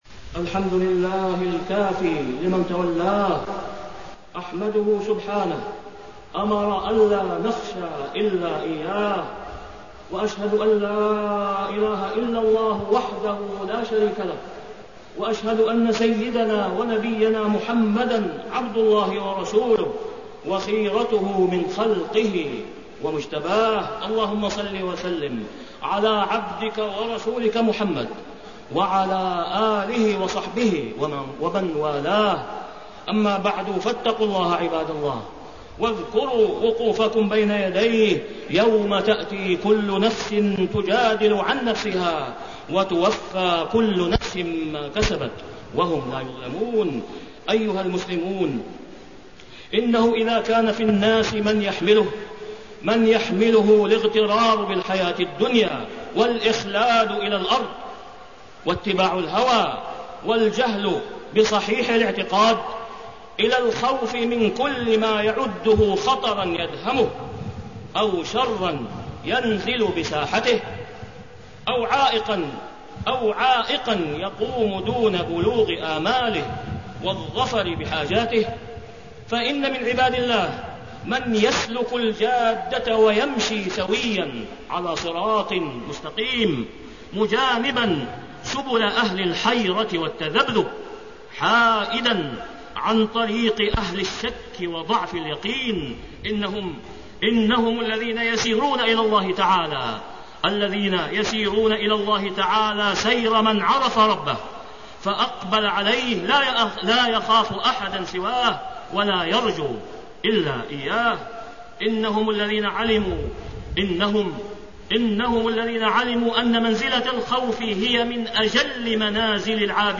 تاريخ النشر ٤ ذو القعدة ١٤٣٠ هـ المكان: المسجد الحرام الشيخ: فضيلة الشيخ د. أسامة بن عبدالله خياط فضيلة الشيخ د. أسامة بن عبدالله خياط الخوف من الله أهميته وآثاره